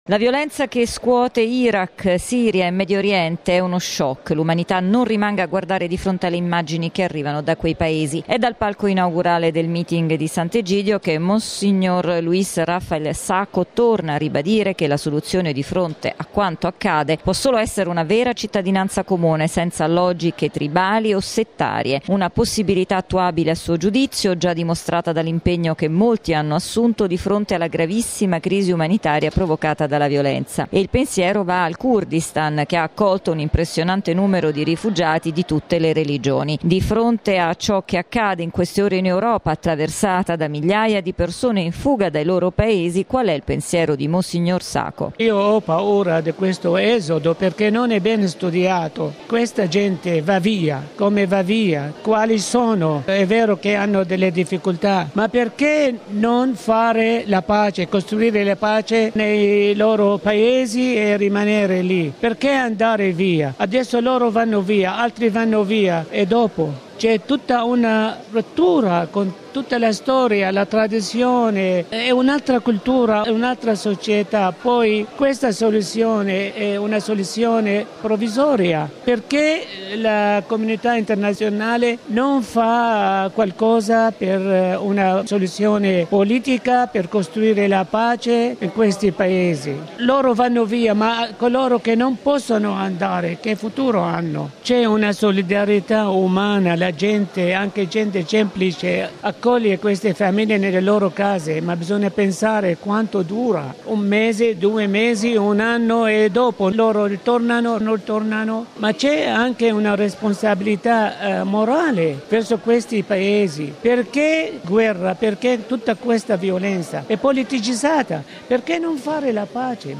La nostra inviata a Tirana